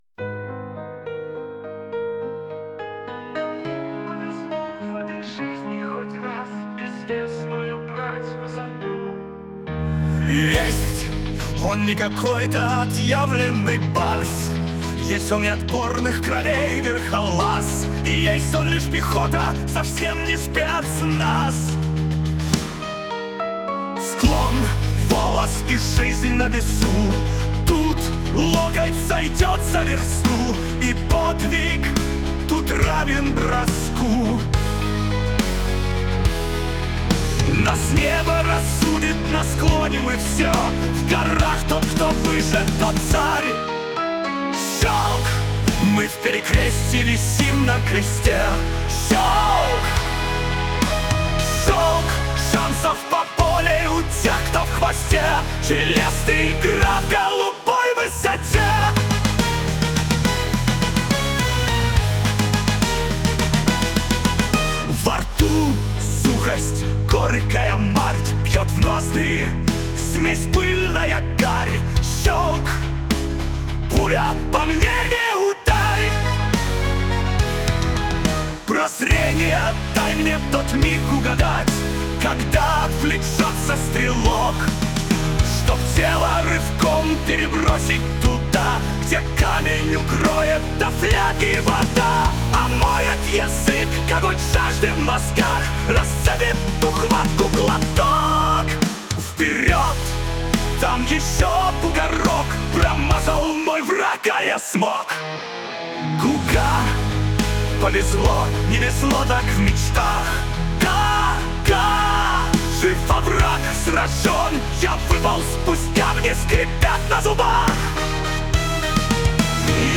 pobeda2.mp3 (4745k) Попытка песни ИИ